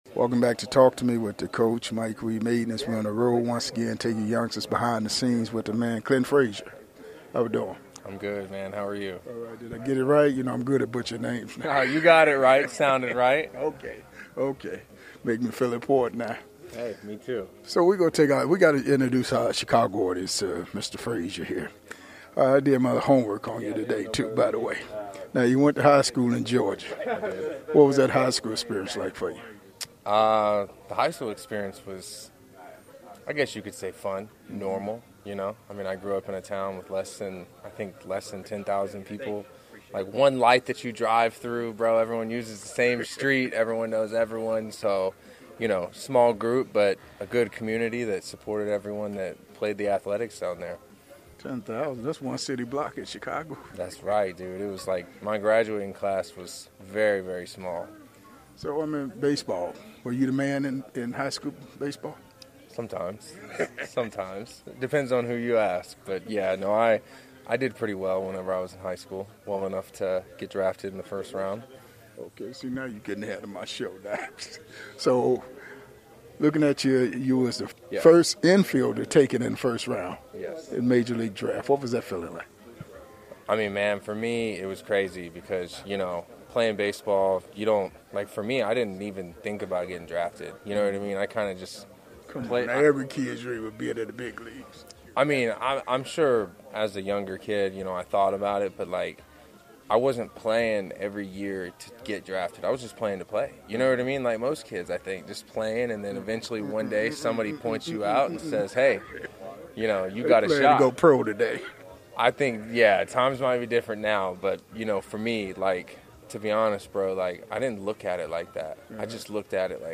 One on one with the MLB stars of the game.